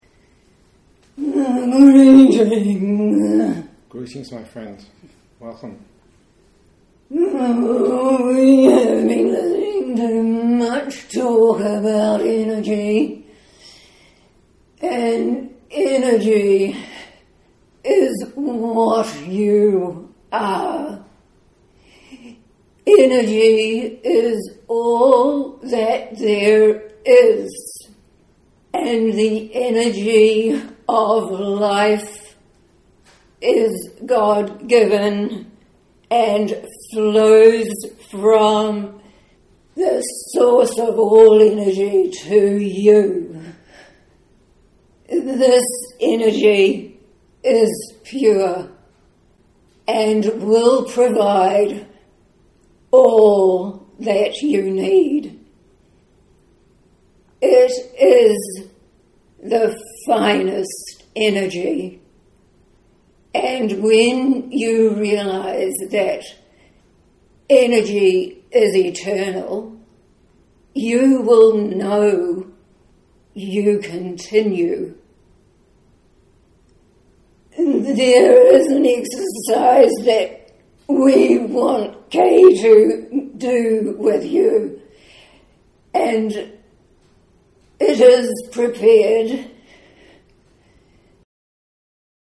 Bear in mind that it is a live recording and please excuse the deficiencies in sound quality.